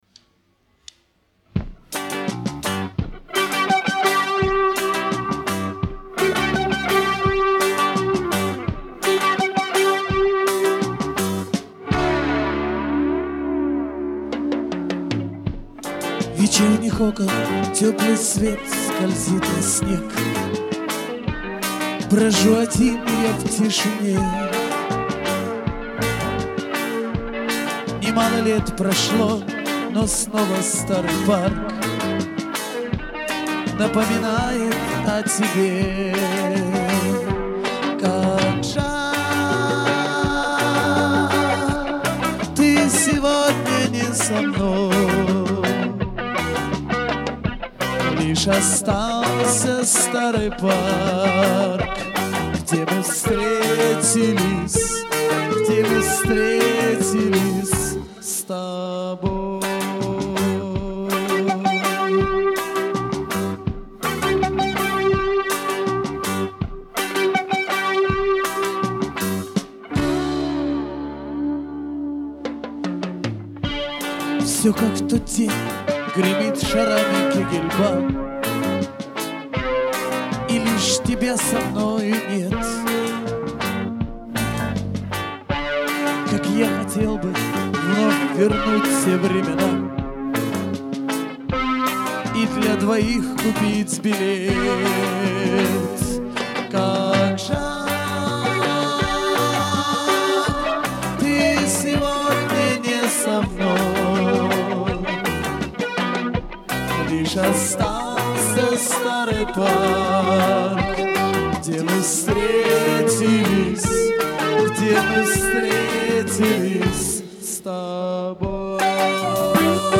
Русский Рок
бас
ударные